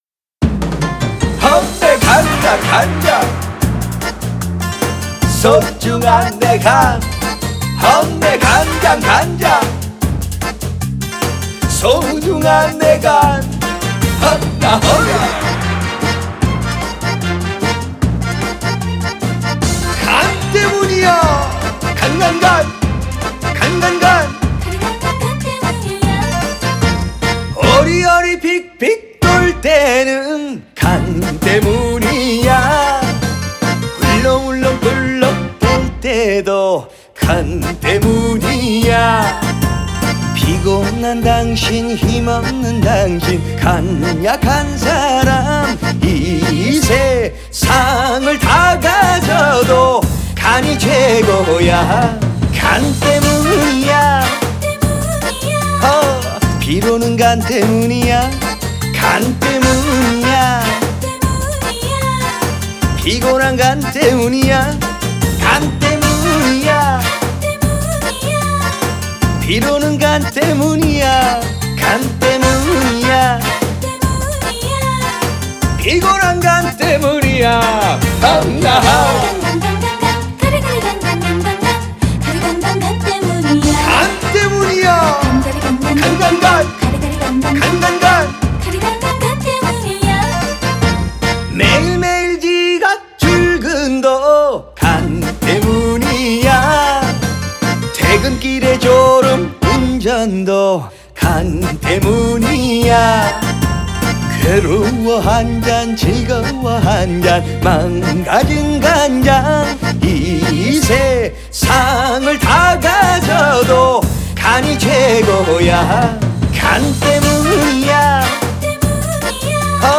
트로트는 저밖에 없는듯 하군요 ㅎㅎ; 외로운 트로트 주자 ㅠㅠ